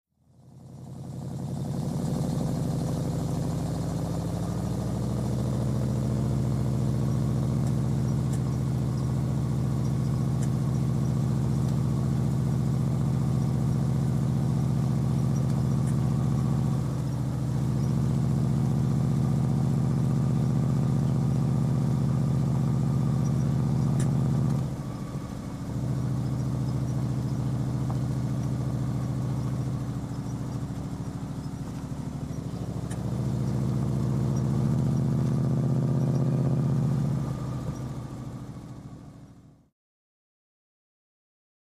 1958 Chevrolet Impala, Interior Pov, Steady Driving @ 20-30 Mph.